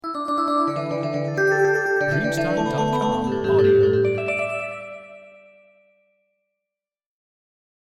Breve carillon asiatico 0002 delle campane
• SFX